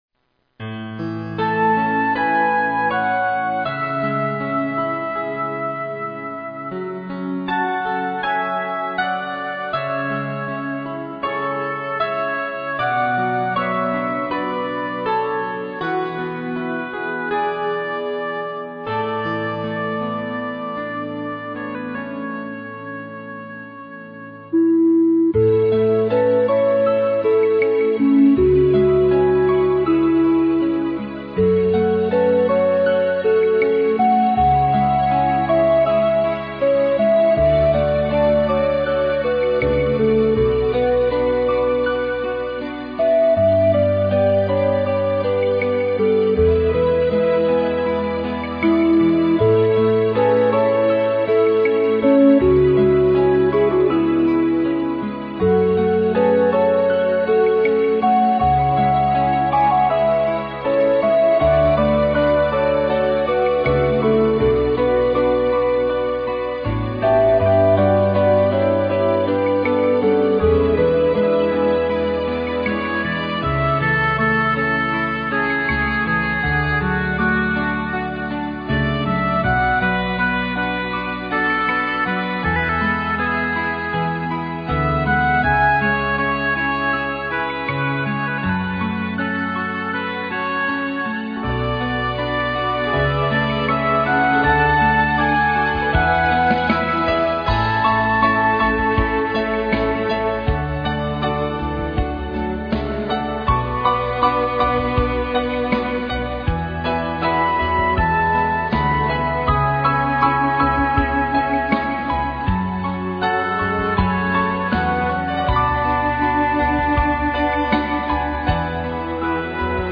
アンサンブル曲